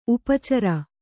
♪ upa cara